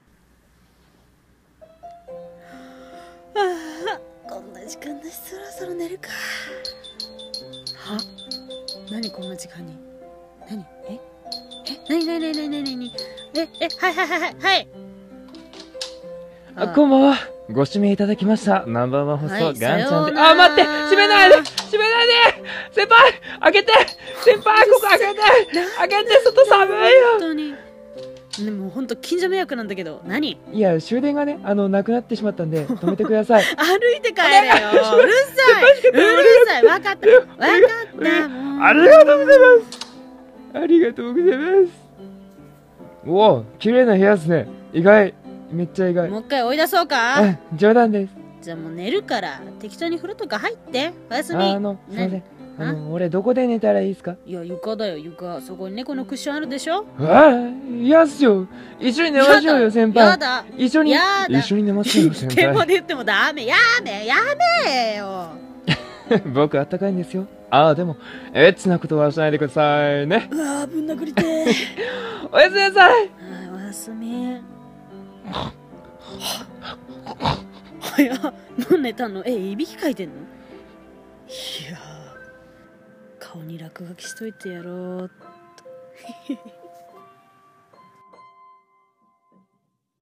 【R16声劇】泊めてください【台本】